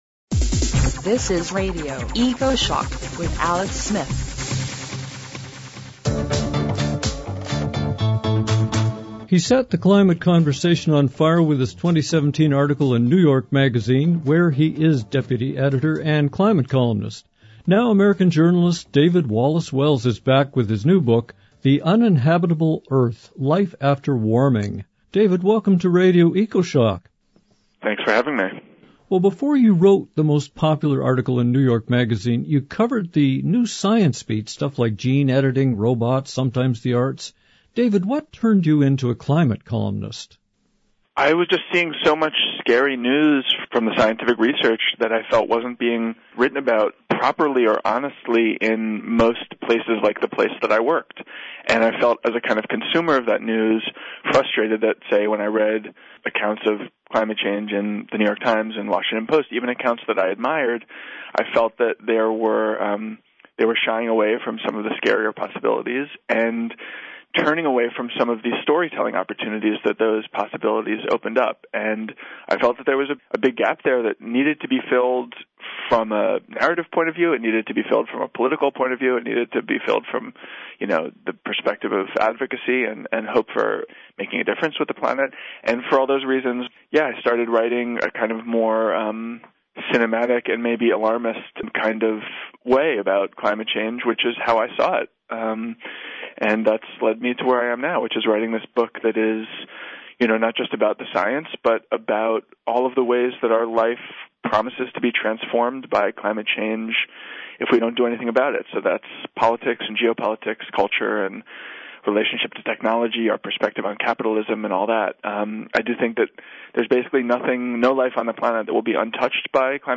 Both interviews are loaded with facts and perspectives only in the Radio Ecoshock climate emergency broadcast.